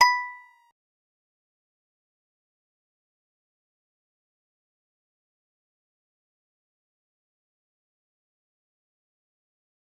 G_Musicbox-B5-pp.wav